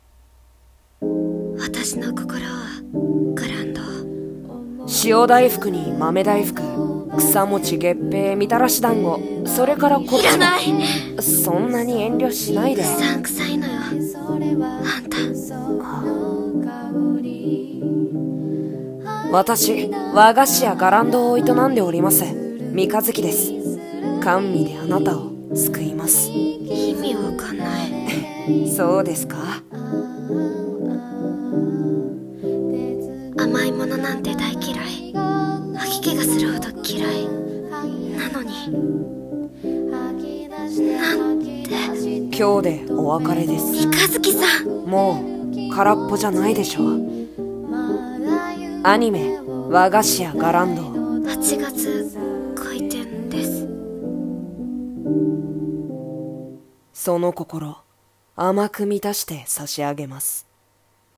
予告風声劇】和菓子屋 伽藍堂